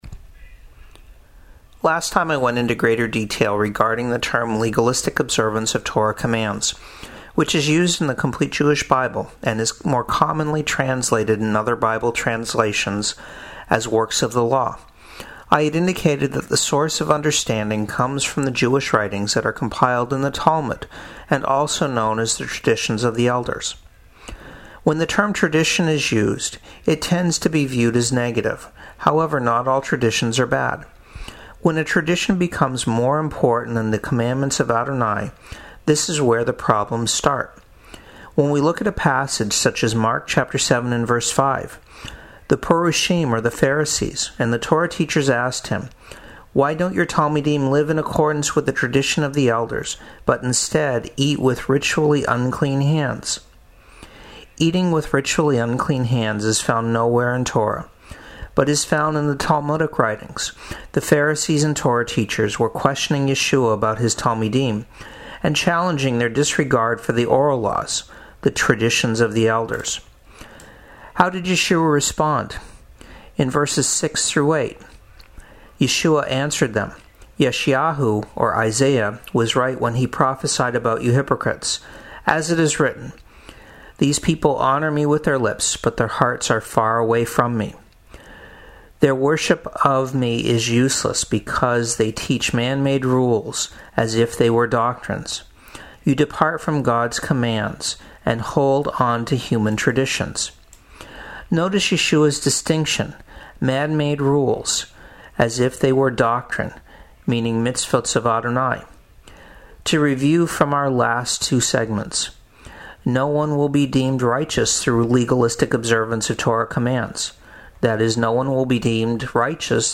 Messianic Perspectives is a radio segment that aired weekdays on radio stations WRKJ 88.5 and WMTP 91.1 in Maine.